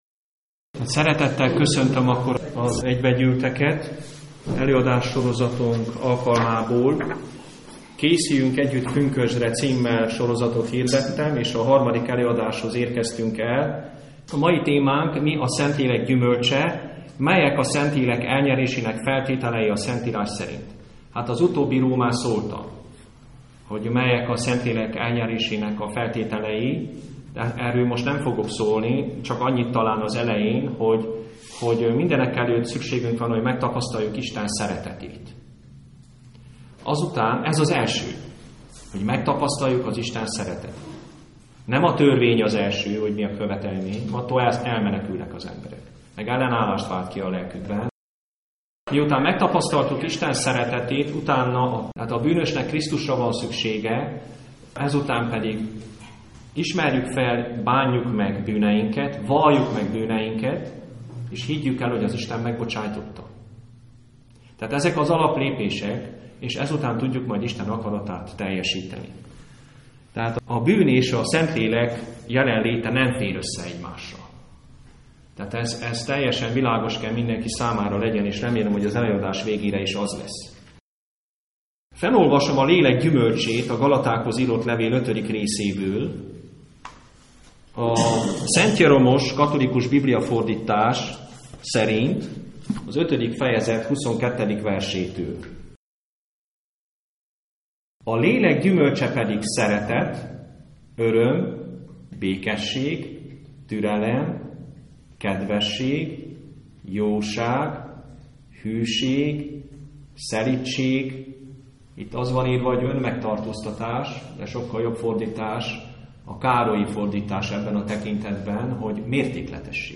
A Lélek gyümölcséről szóló előadás itt hallgatható meg!